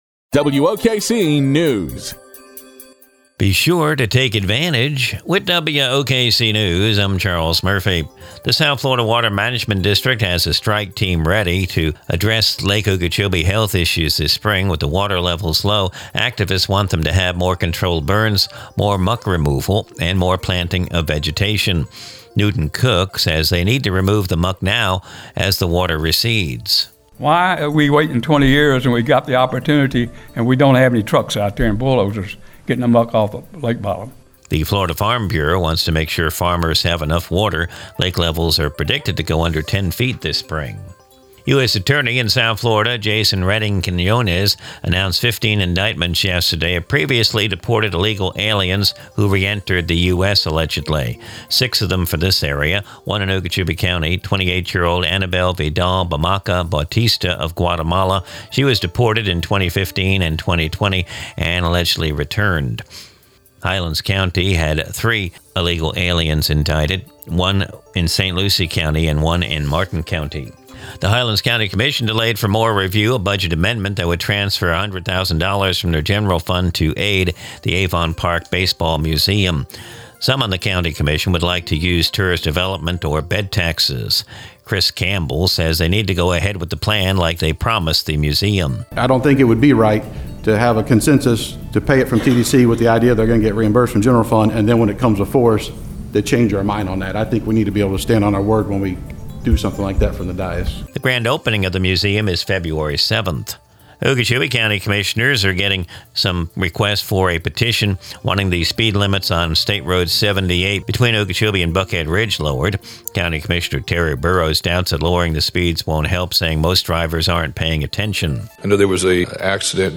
NEWS
Recorded from the WOKC daily newscast (Glades Media).